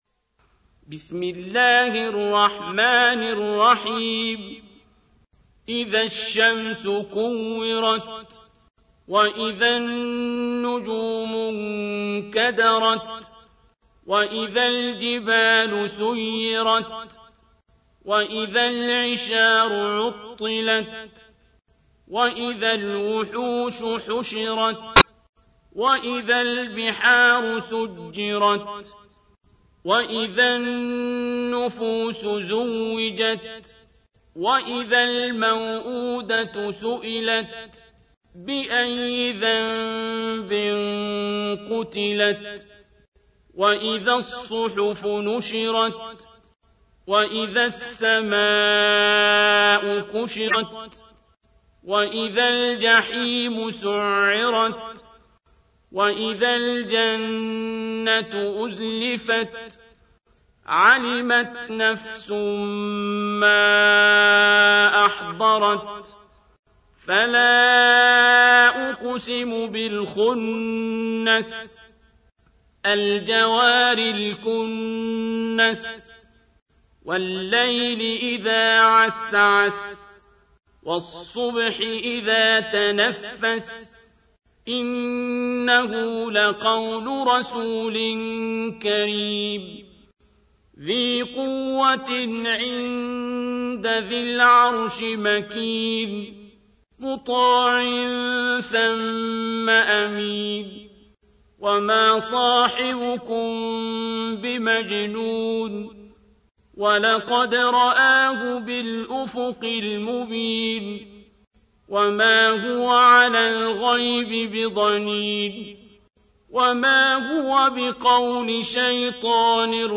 تحميل : 81. سورة التكوير / القارئ عبد الباسط عبد الصمد / القرآن الكريم / موقع يا حسين